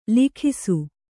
♪ likhisu